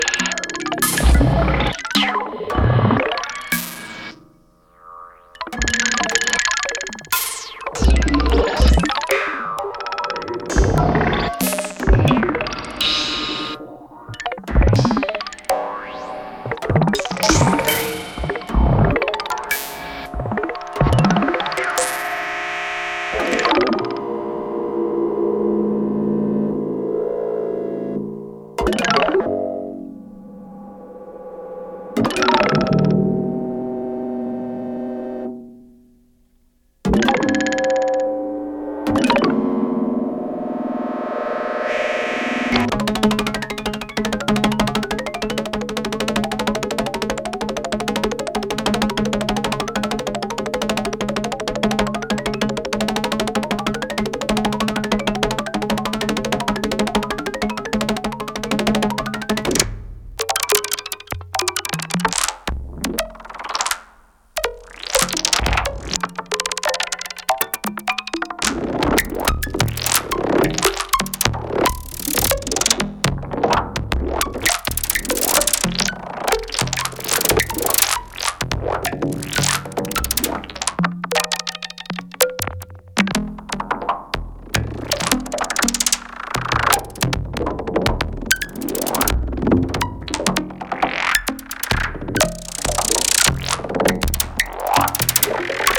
Electronic
designed for the surround-sound medium.